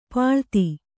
♪ pāḷti